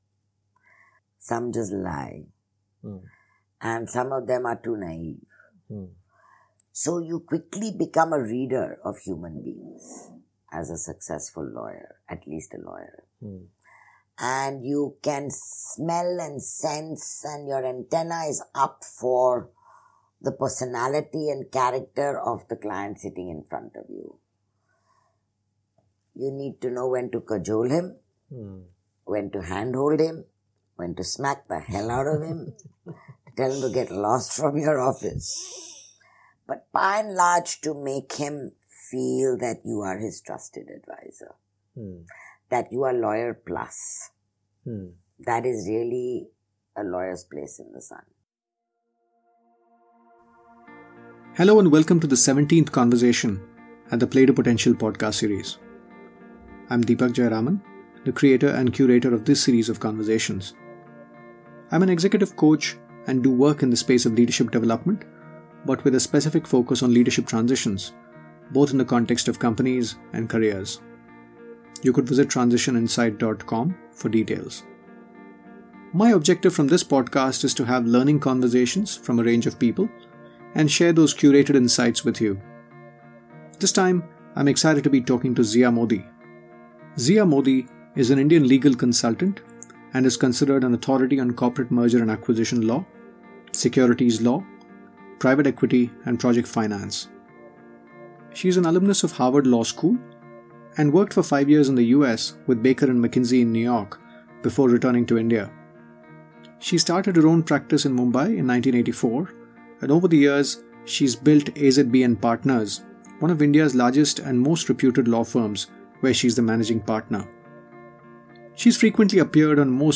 In our conversation, we spoke about her take on how people should think about law as a career, her take on women growing to become leaders and the leaky pipeline there, trade-offs between work and life, what it takes to be a trusted advisor with a client, what it takes to build an institution of repute and more. Without further ado, over to my conversation with Zia.